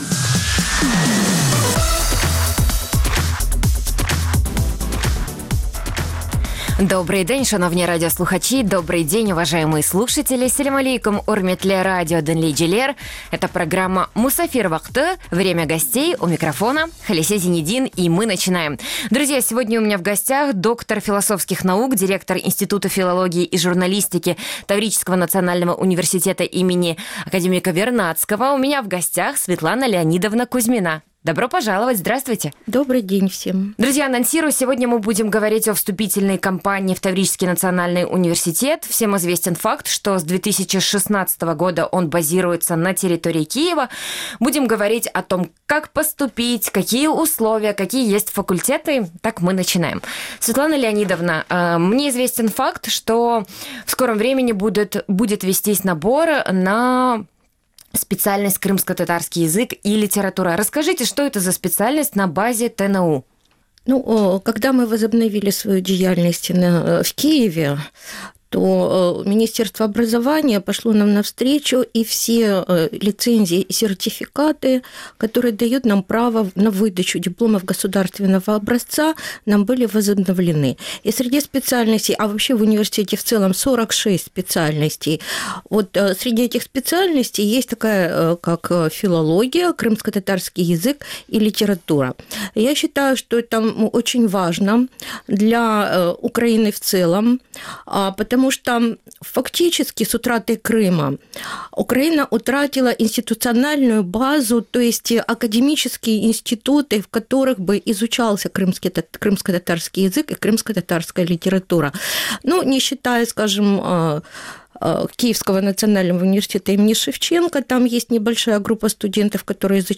Эфир можно слушать Крыму в эфире Радио Крым.Реалии (105.9 FM), а также на сайте Крым.Реалии.